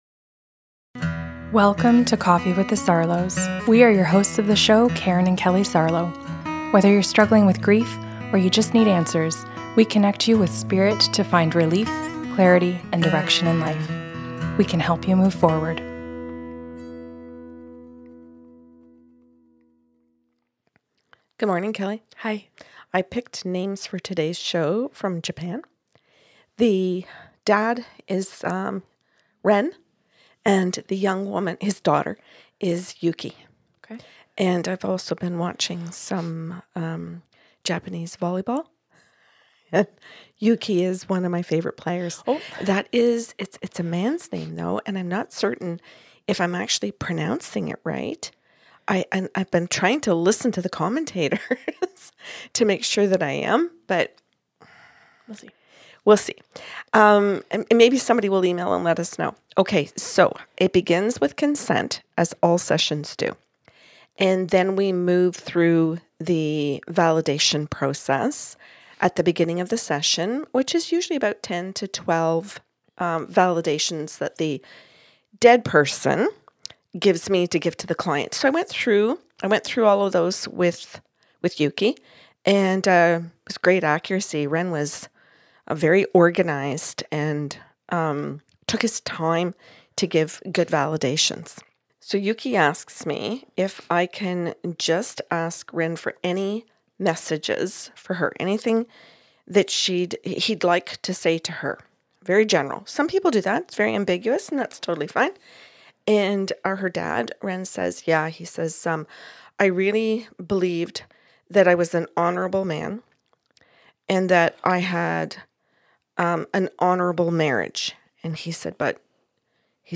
YMC Special Episode - A Conversation